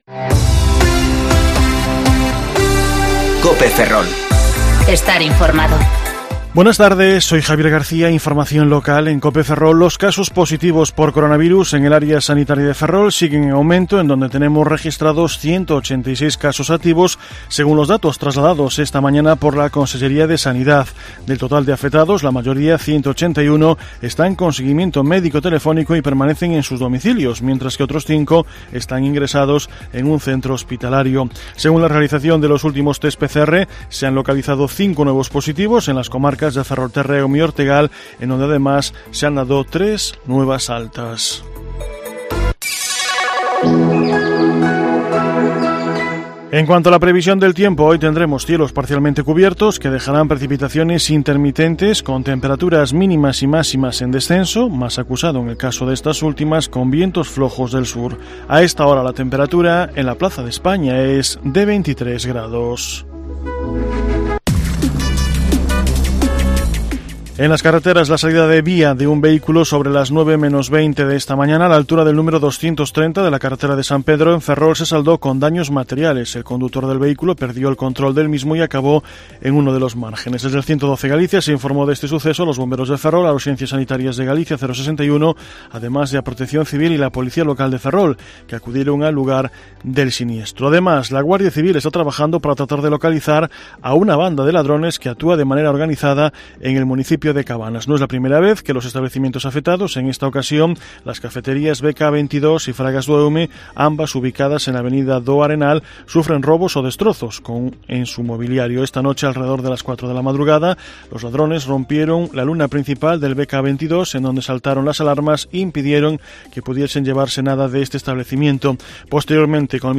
Informativo Mediodía COPE Ferrol 14/9/2020 (De 14,20 a 14,30 horas)